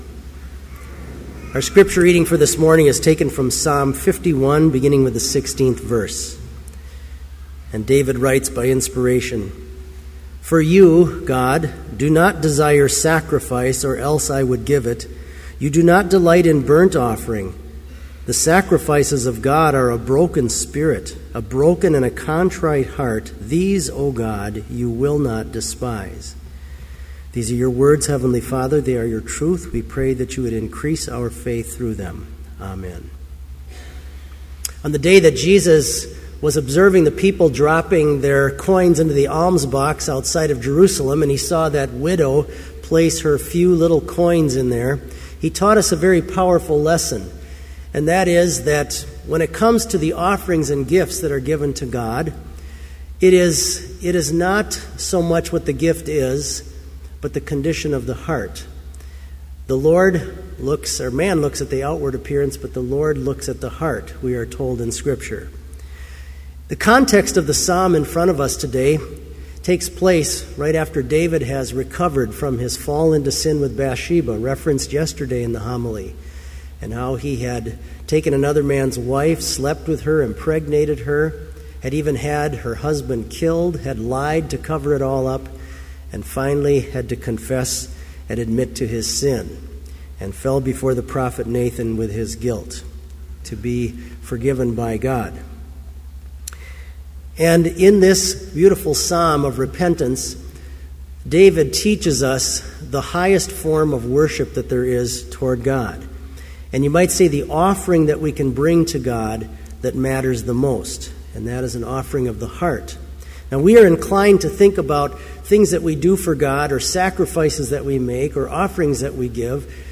Complete Service
• Homily
• * We sing the Lord's Prayer using Hymn 384, Our Father, Throned in Heaven Above
This Chapel Service was held in Trinity Chapel at Bethany Lutheran College on Wednesday, October 9, 2013, at 10 a.m. Page and hymn numbers are from the Evangelical Lutheran Hymnary.